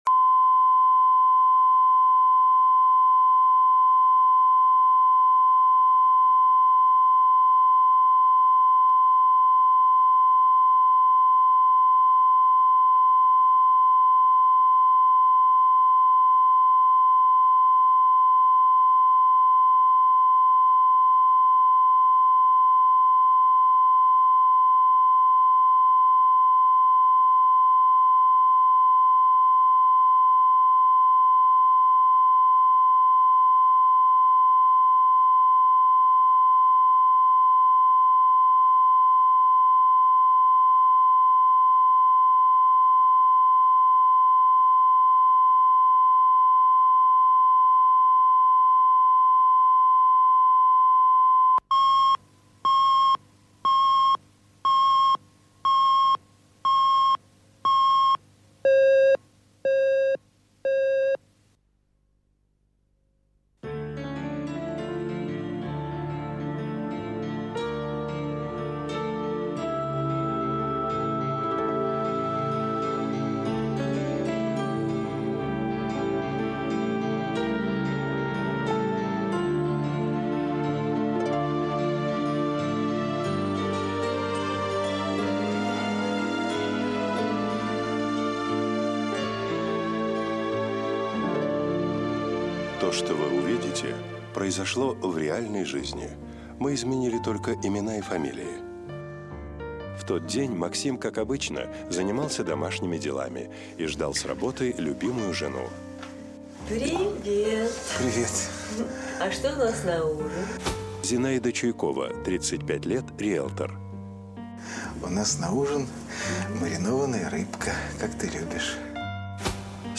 Аудиокнига Жажда любви | Библиотека аудиокниг